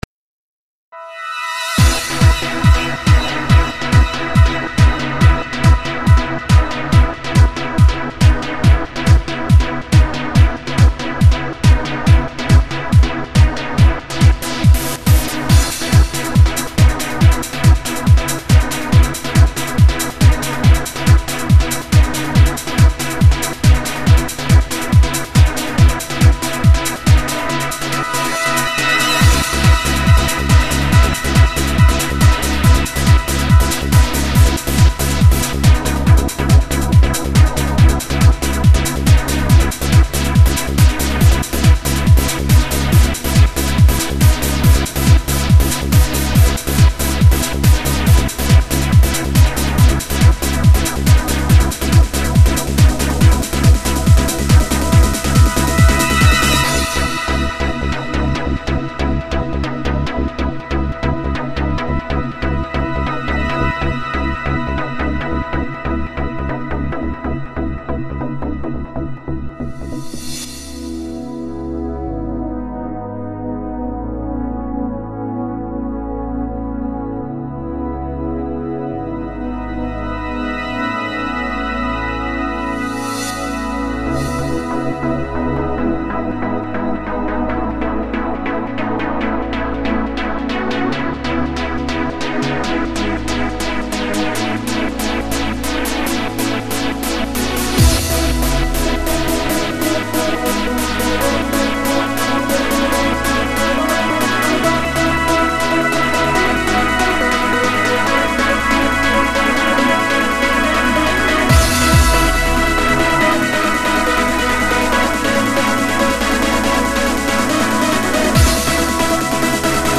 Всего в чарте участвовало 19 танцевальных композиций череповецких и вологодских мьюзикмейкеров.